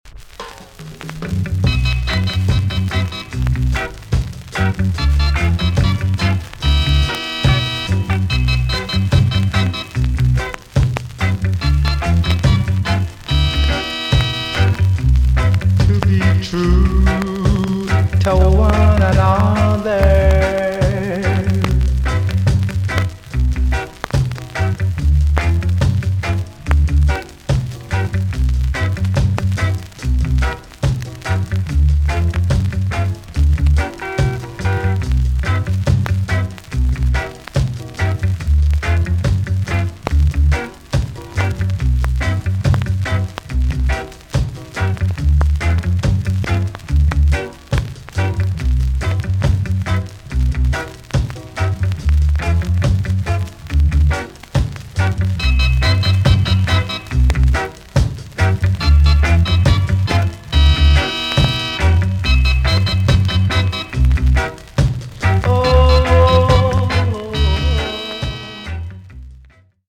B.SIDE Version
VG ok チリノイズが入ります。